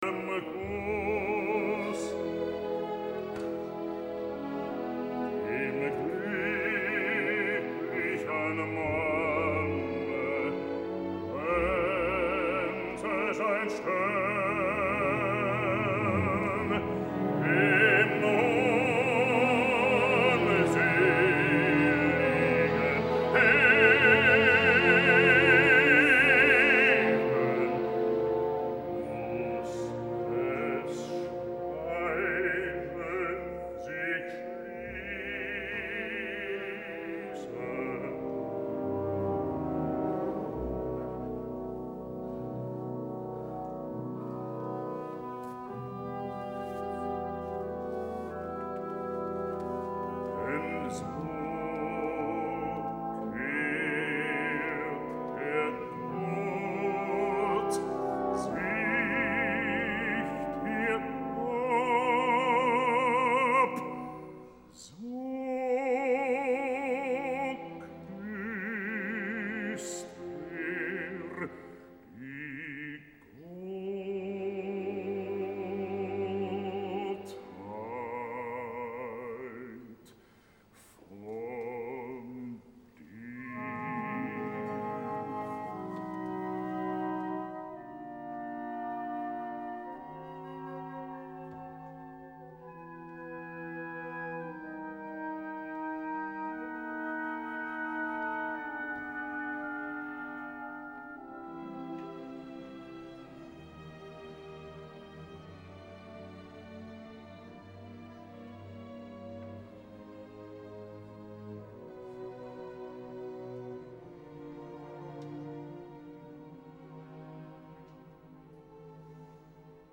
5.3 mb Wagner, Wotan's Farewell, Die Walküre, Act III (This audio has a vocal part not shown in your Anthology score)